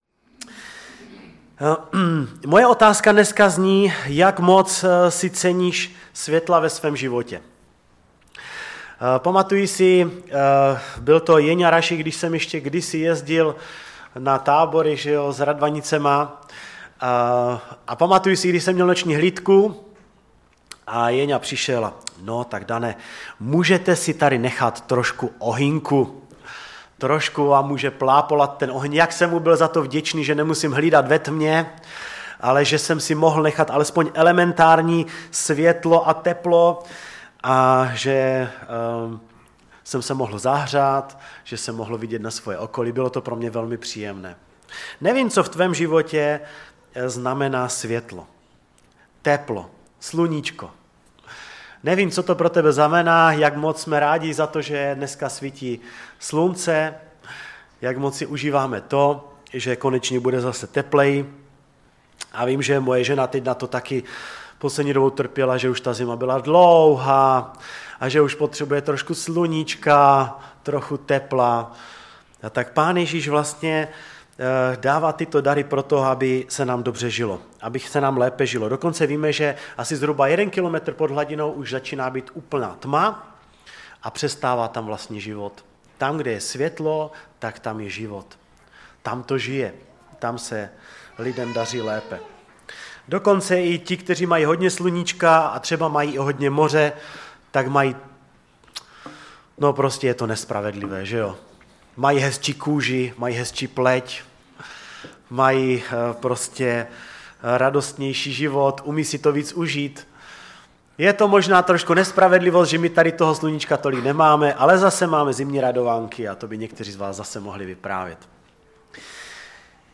Kázání
ve sboře Ostrava-Radvanice.